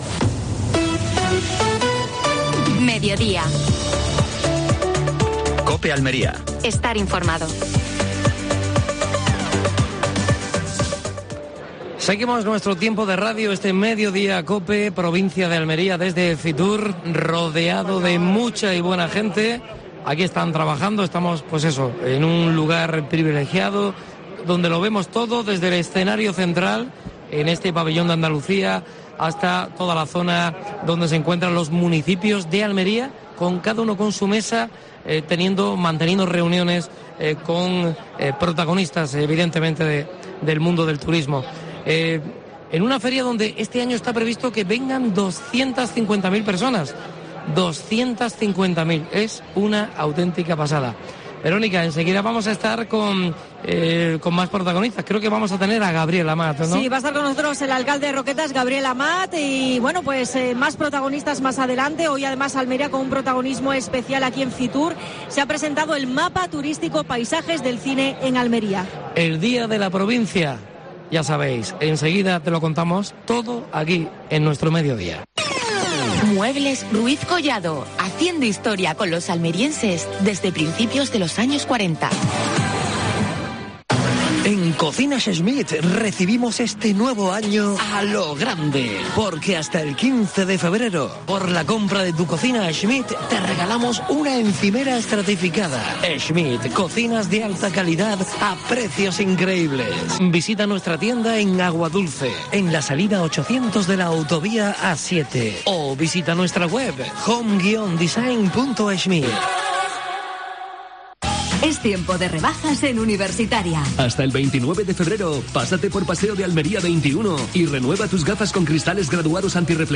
AUDIO: Actualidad en Almería. Entrevista a Gabriel Amat (alcalde de Roquetas de Mar) en FITUR.